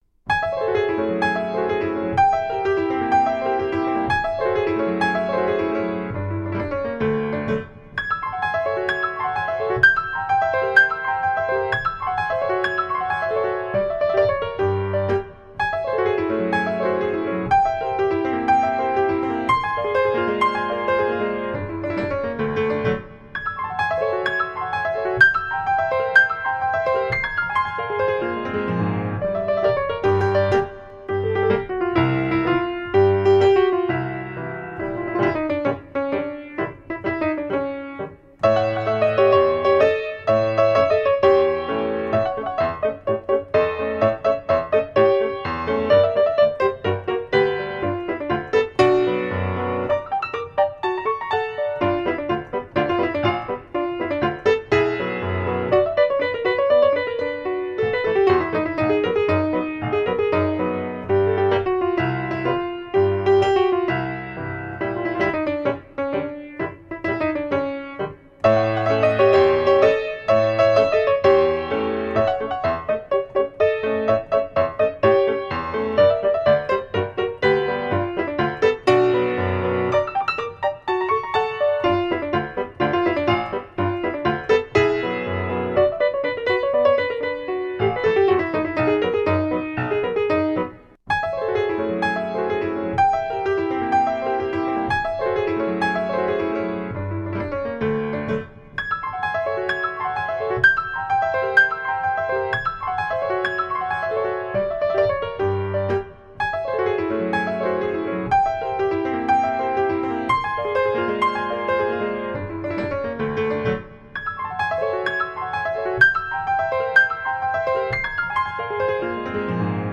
Exquisite original melodic pieces.